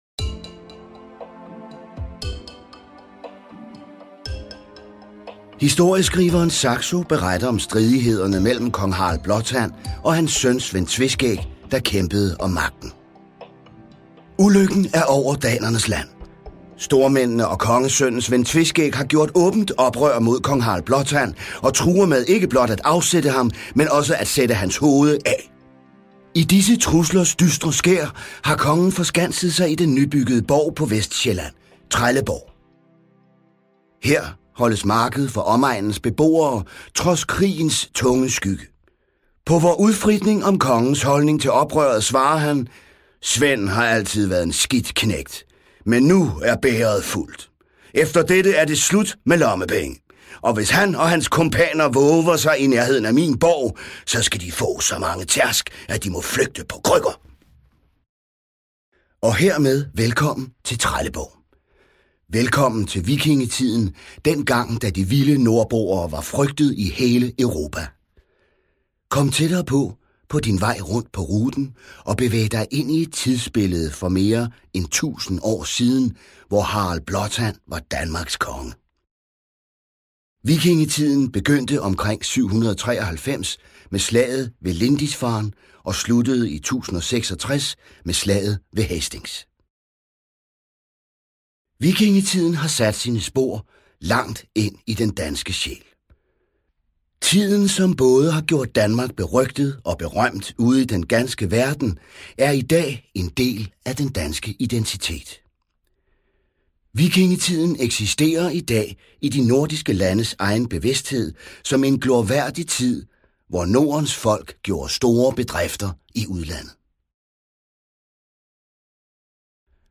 PodWalk (Danmark)
En dramatiseret tur på vikingeborgen Trelleborg, der skildrer kongens kampe, kristningen af danskerne og det dramatiske opgør med sønnen Svend Tveskæg